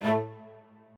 strings6_35.ogg